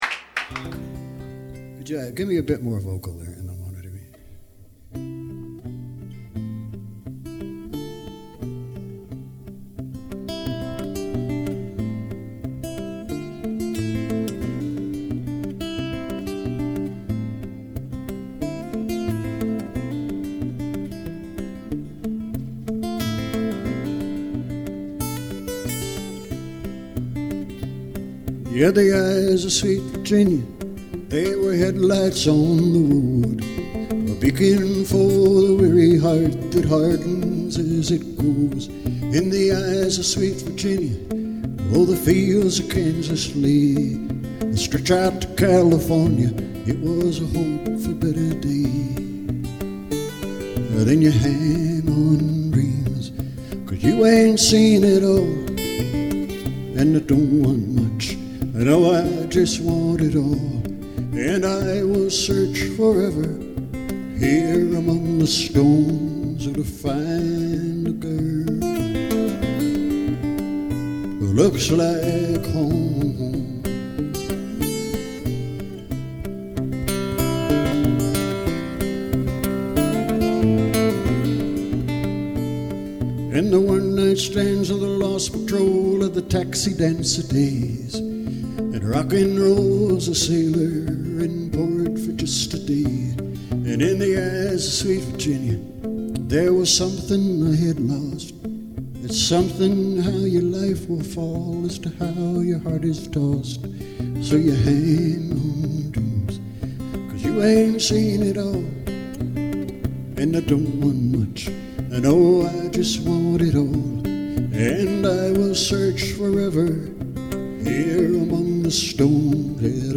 Recorded live in 2000 in Dalry, Scotland.